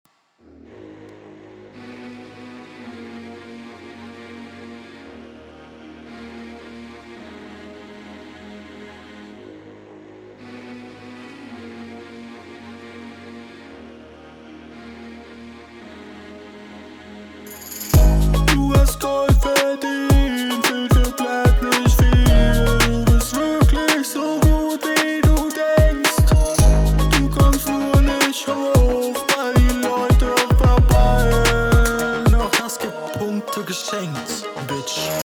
Auch hier wieder nur links.